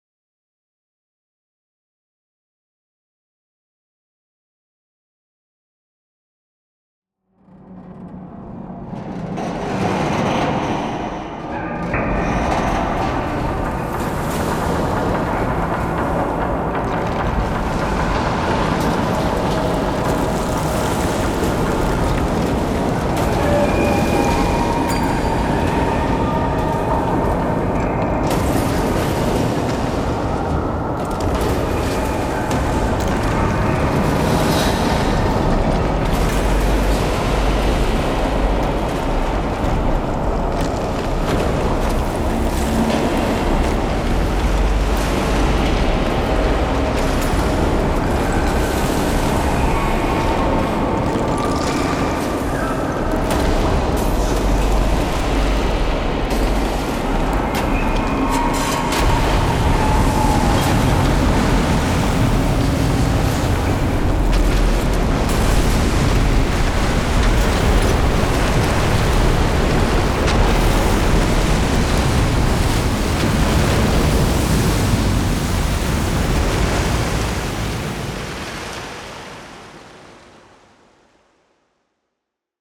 TreeSound_Loopable.wav